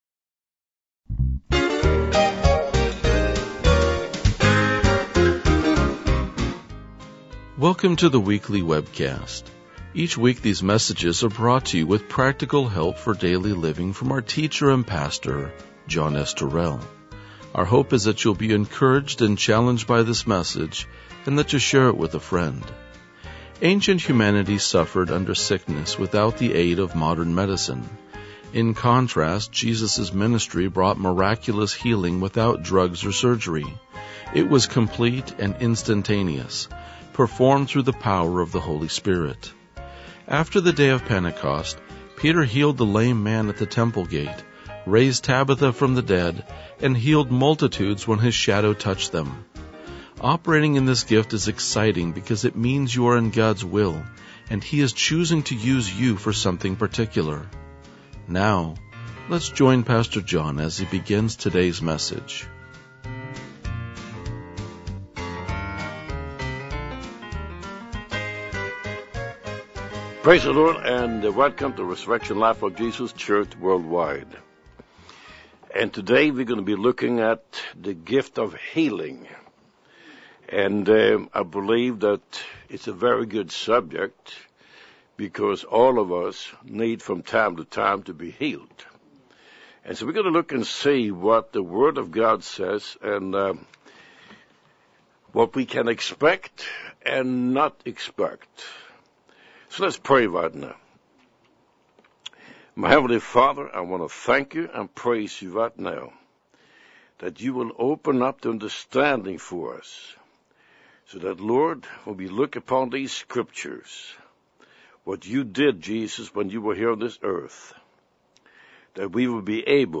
RLJ-2025-Sermon.mp3